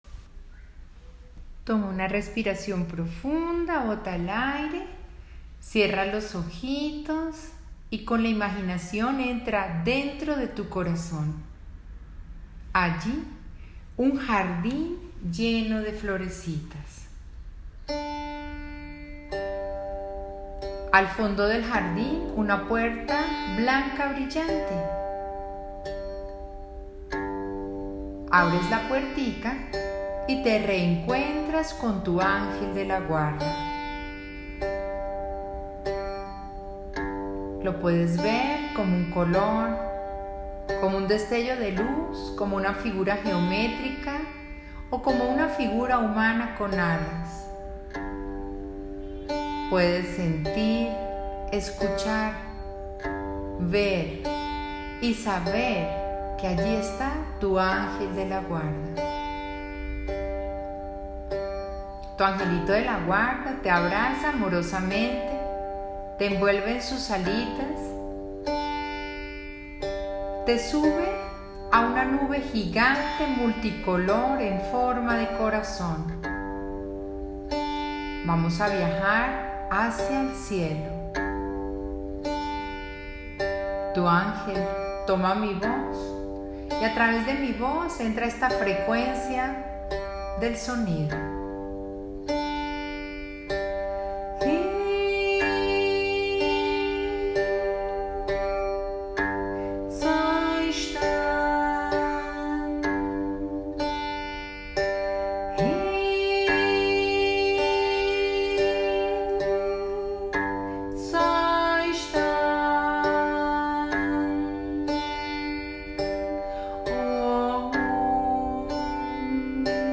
VIAJE ANGELICAL - ANGELIC SOUND HEALING